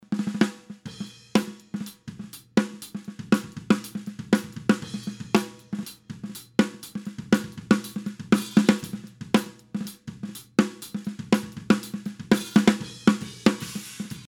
Wie oben beschriebengeht es mir darum die Ghostnotes sauber abzubilden.
Oder ist mein Basis-Signal einfach zu schlecht (im Anhang)?